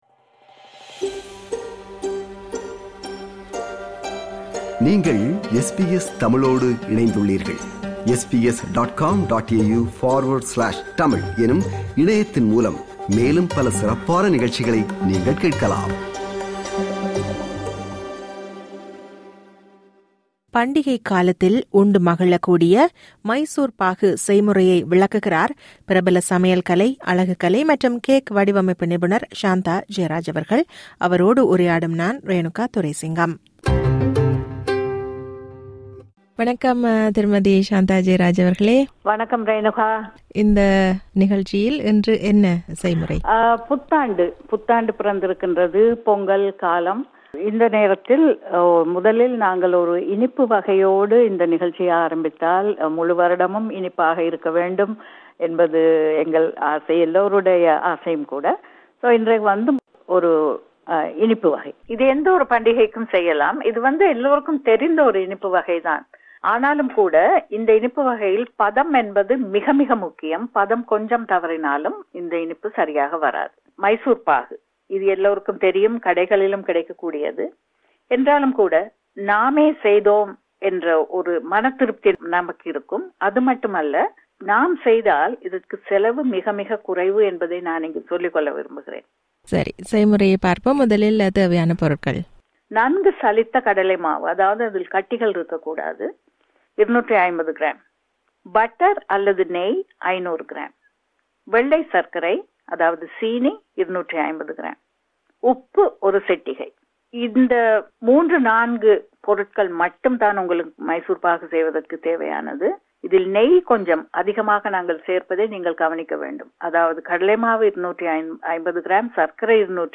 அவரோடு உரையாடுகிறார்